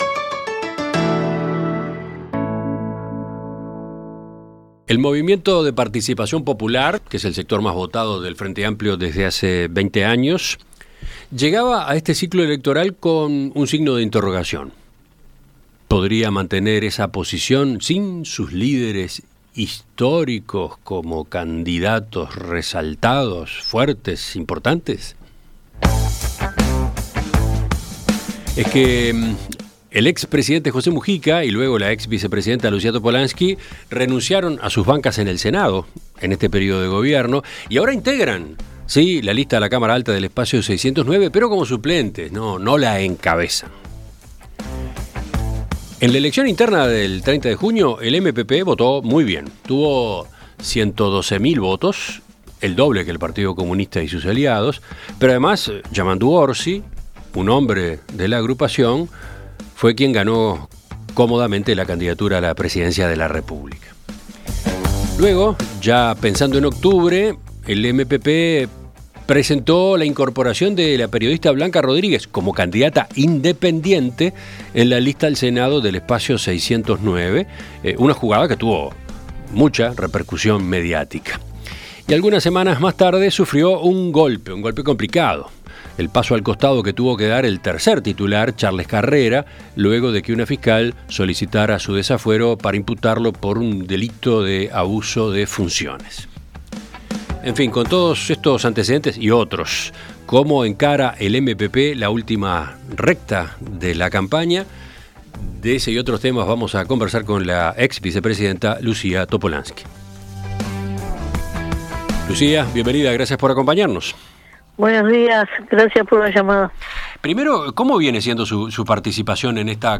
En Perspectiva Zona 1 – Entrevista Central: Lucía Topolansky - Océano
¿Cómo encara entonces el Movimiento de Participación Popular la última recta de la campaña?Conversamos con la ex vicepresidenta Lucía Topolansky.